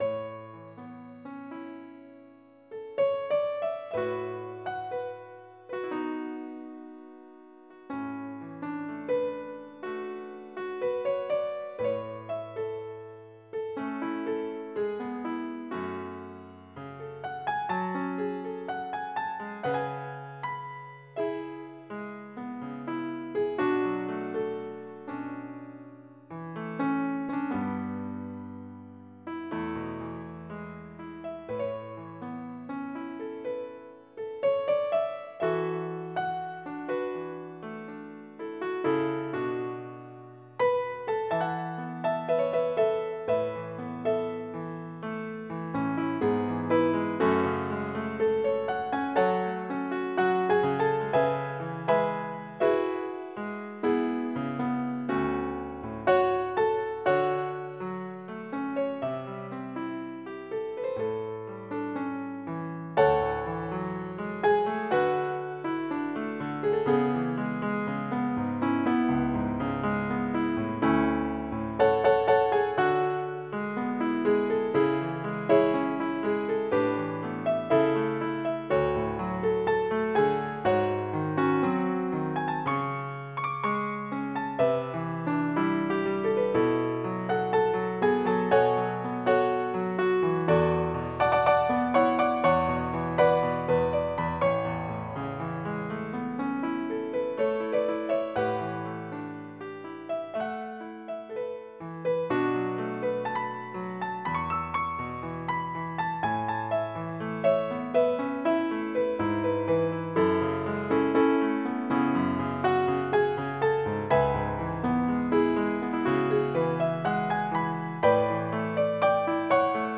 MIDI Music File
Type General MIDI
jazz87.mp3